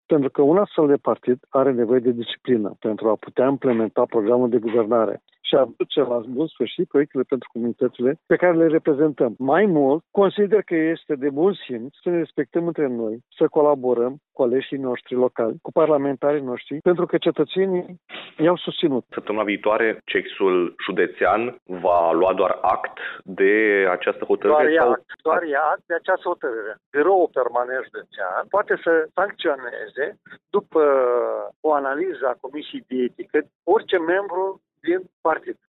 Într-o intervenţie la Radio Iaşi, liderul filialei judeţene a PSD, Maricel Popa, a afirmat că săptămâna viitoare, Comitetul Executiv Județean ar urma să ia doar act de hotărârea Biroului Permanent Județean Iași.